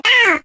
yoshi_oof.ogg